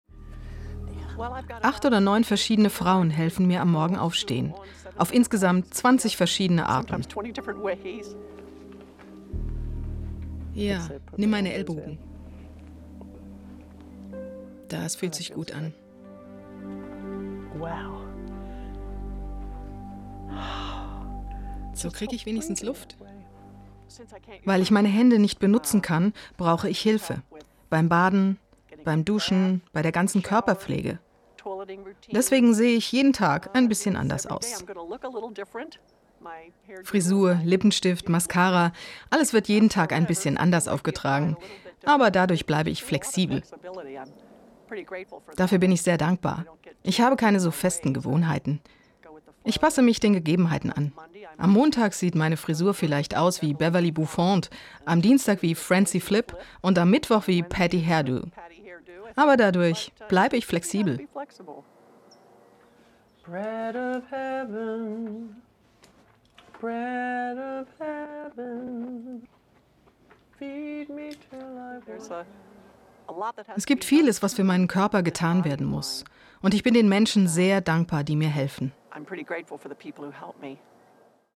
deutsche Sprecherin.
Kein Dialekt
Sprechprobe: Industrie (Muttersprache):